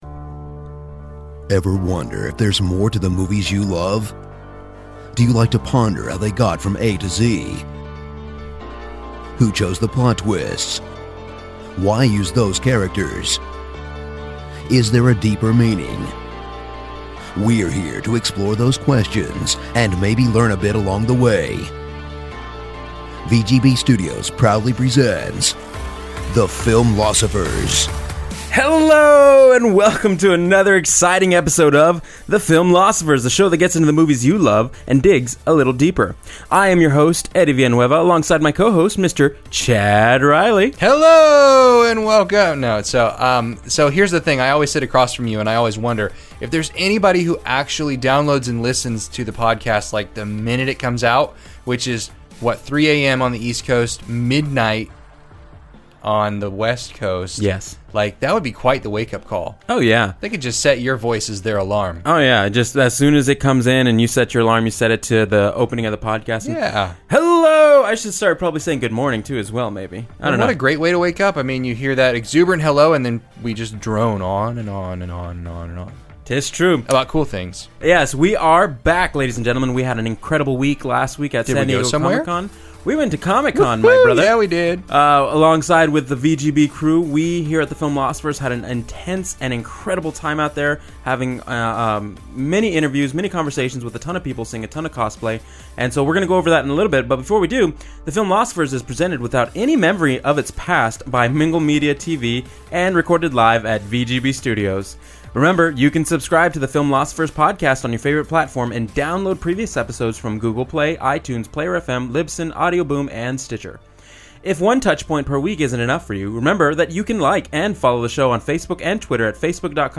The Filmlosophers step right out of the theater and into the recording studio to discuss Jason Bourne (2016), which features the return of Matt Damon in the title role. In the nine years that have passed since The Bourne Ultimatum (2007), has Bourne gotten any close to finding closure and a sense of self?